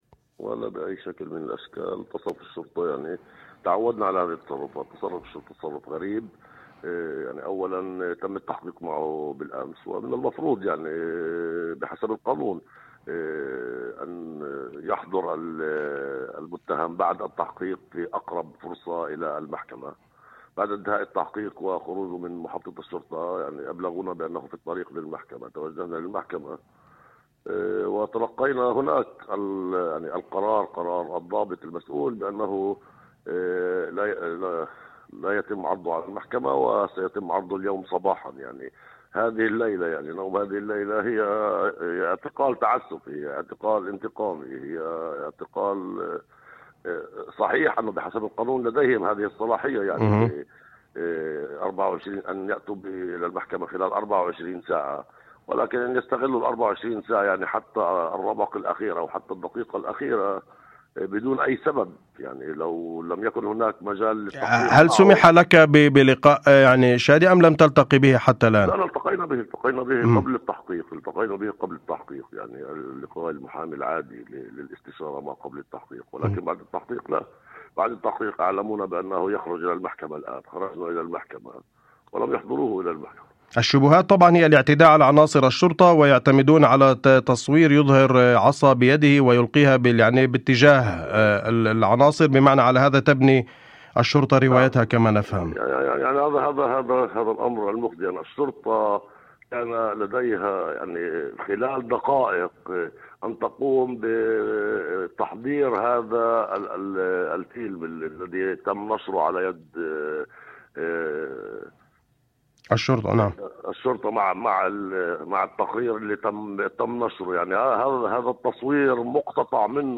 في مداخلة لبرنامج "أول خبر" على إذاعة الشمس